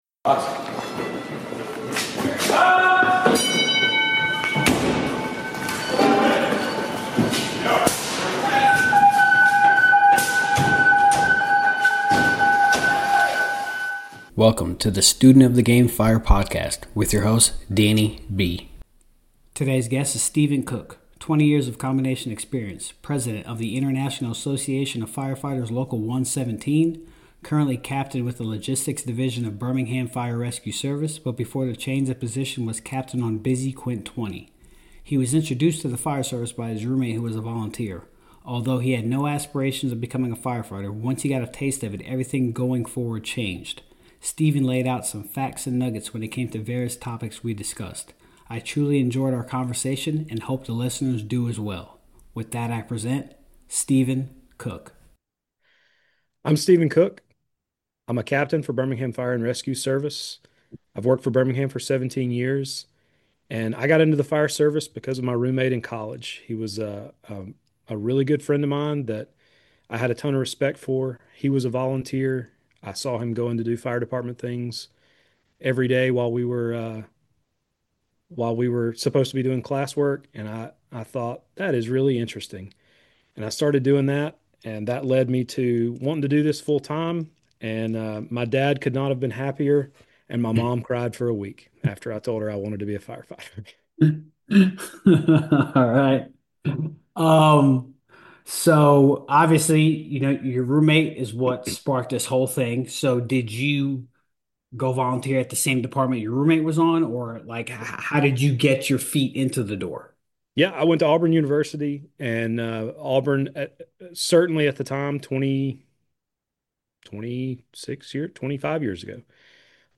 I truly enjoyed our conversation and hope the listeners do as well.